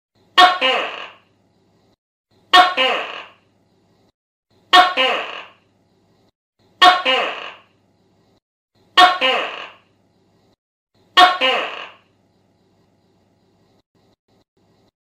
Genre: Nada dering binatang